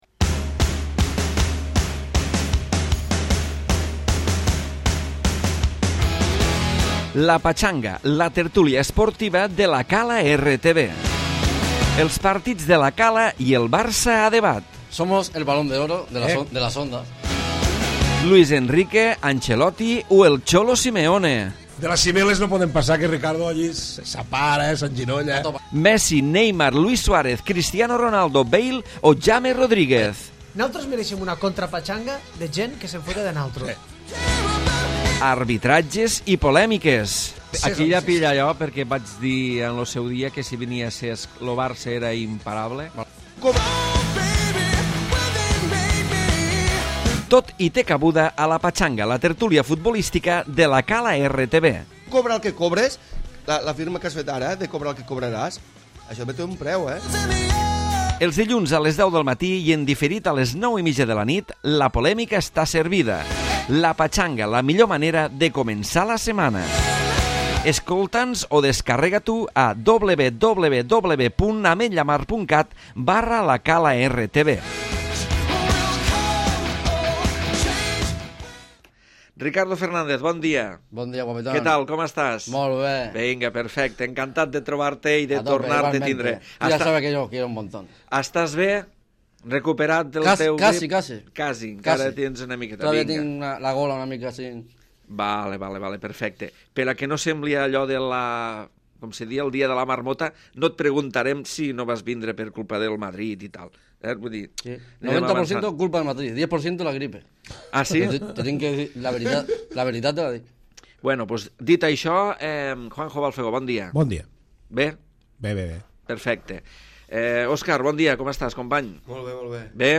Tertúlia d'actualitat esportiva